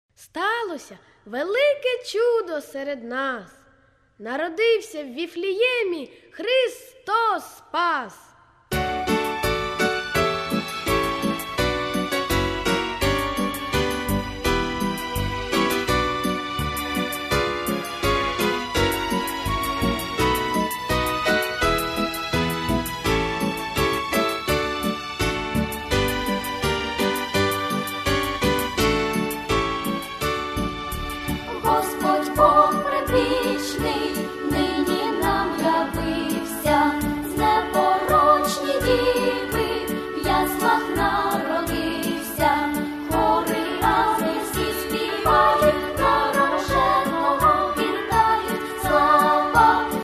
Різдвяні (94)